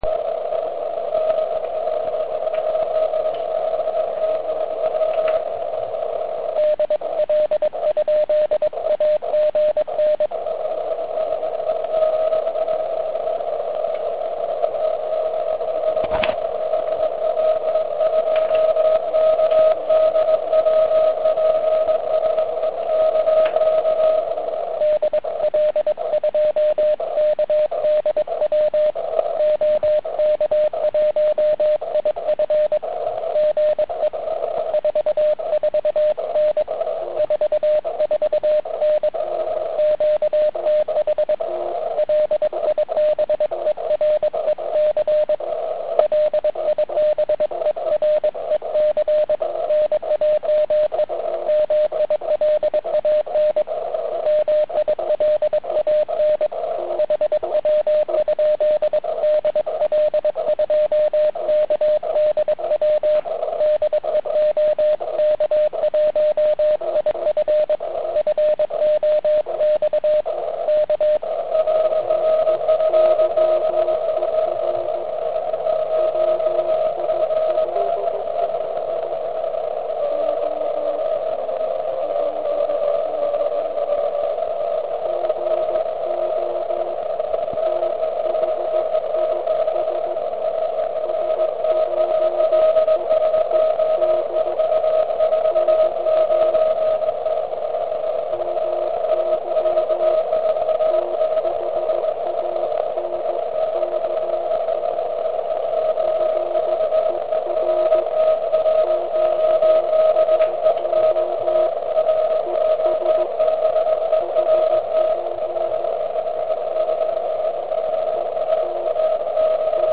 Ten se mi malinko ztrácel v šumu, ale časem signál vyrostl.